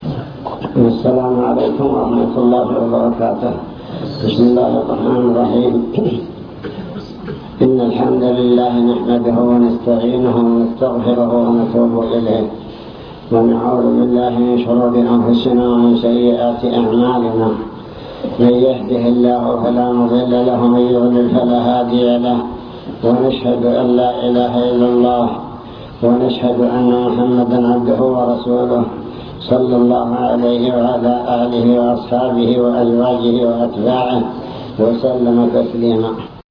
المكتبة الصوتية  تسجيلات - محاضرات ودروس  محاضرة بعنوان شكر النعم (3)